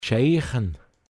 Pinzgauer Mundart Lexikon
Mundart Begriff für geschehen